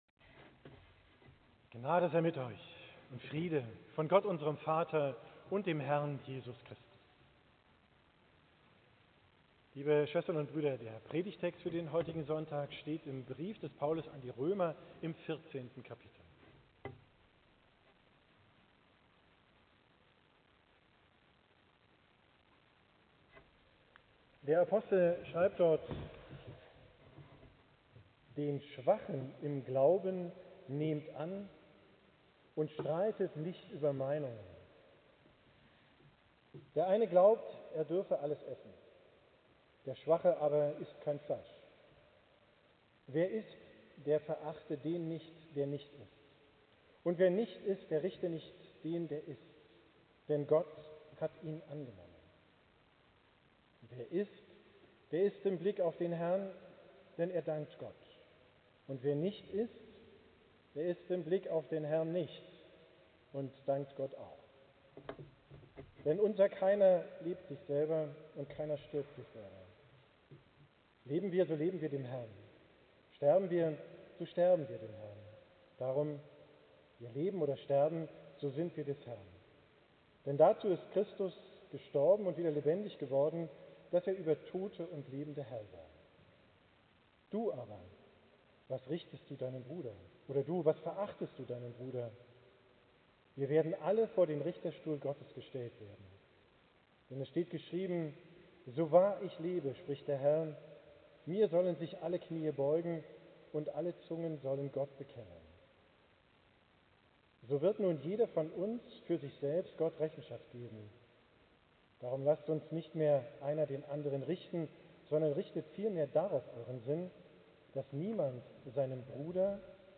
Predigt vom vorletzten Sonntag im Kirchenjahr, 17.